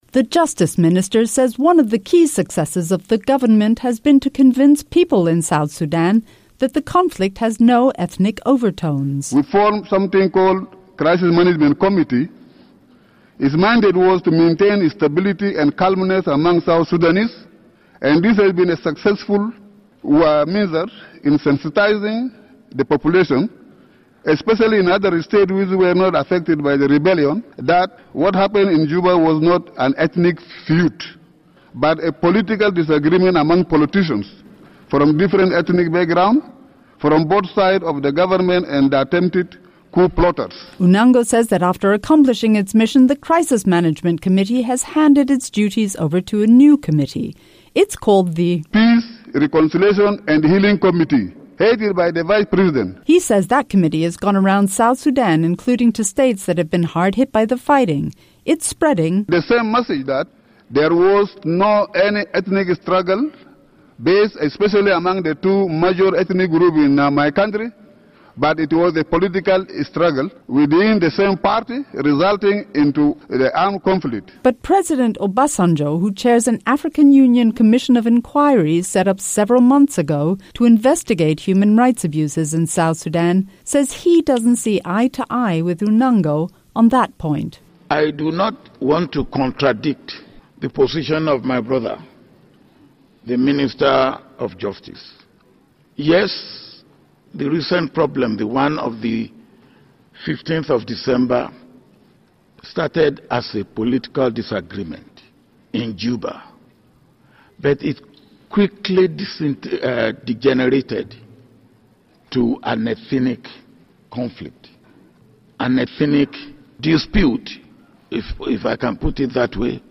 UN Human Rights Council hearing on South Sudan.